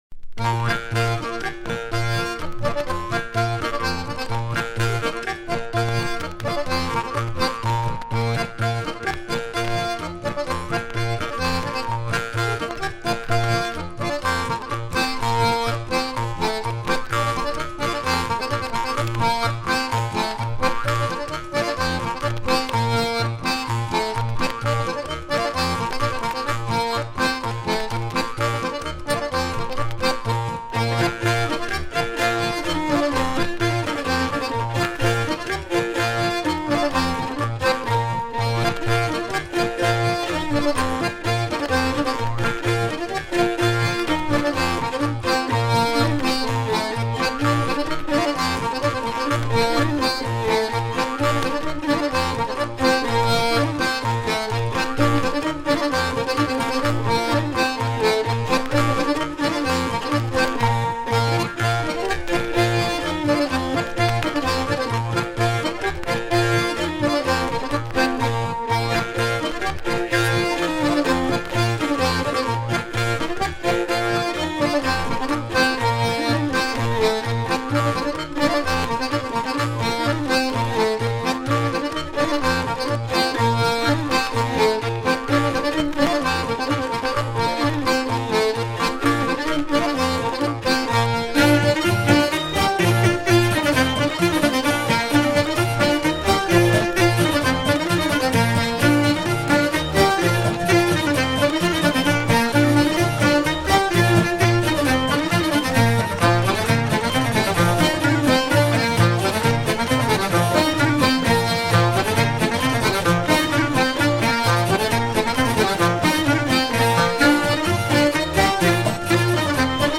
Airs du répertoire des sonneurs de vielle
danse : polka
Pièce musicale éditée